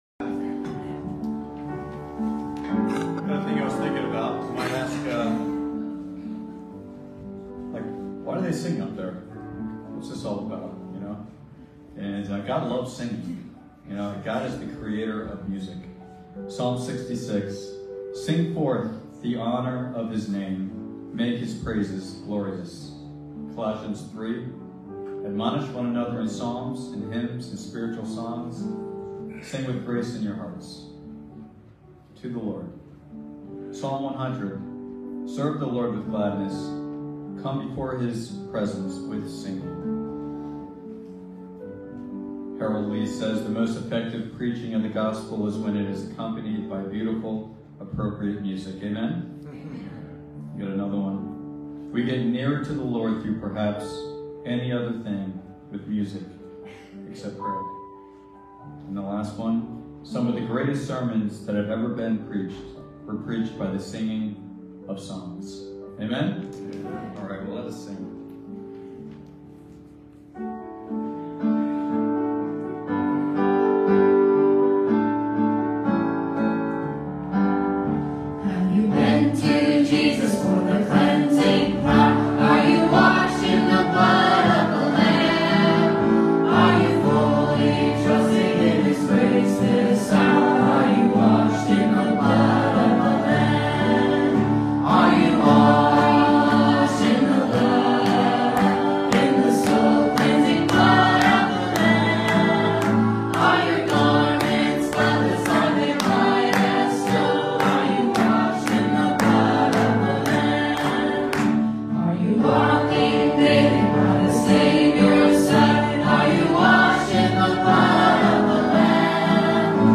Passage: Romans 8:18-30 Service Type: Sunday Morning